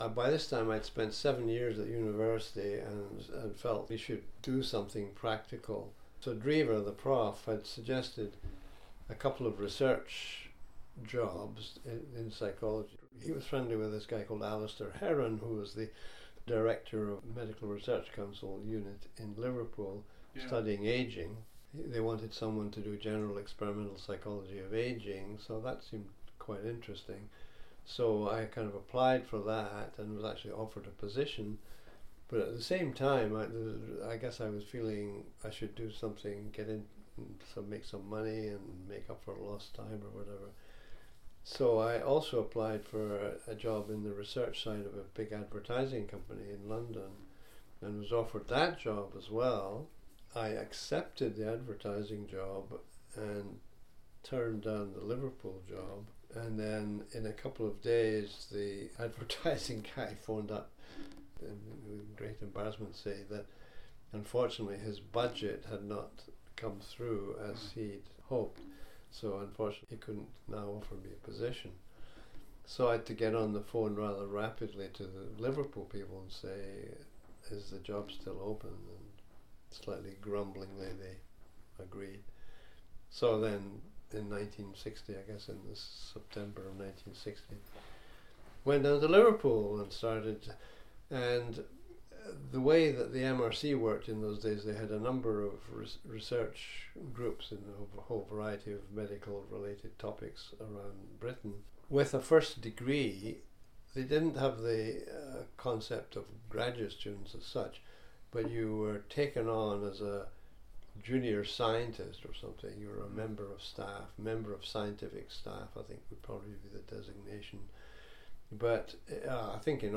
Here Dr. Craik describes the process in which he decides to take an MRC position and the associated doctoral work at the University of Liverpool: